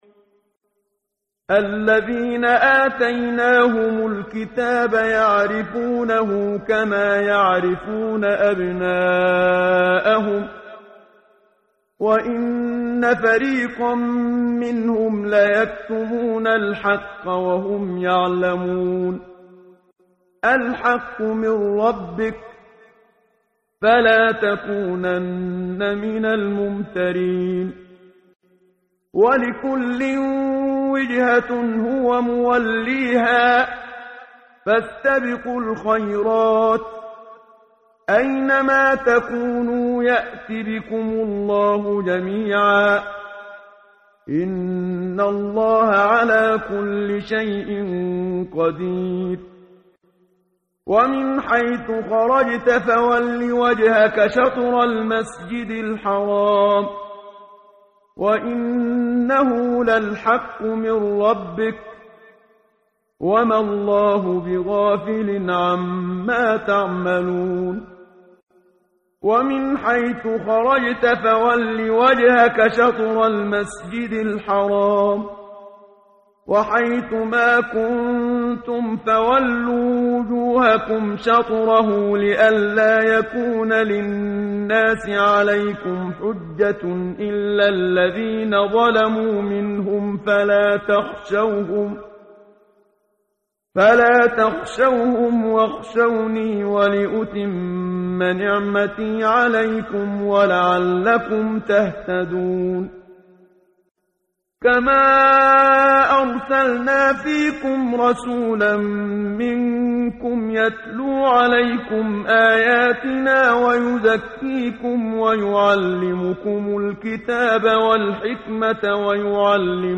ترتیل صفحه 23 سوره مبارکه بقره (جزء دوم) از سری مجموعه صفحه ای از نور با صدای استاد محمد صدیق منشاوی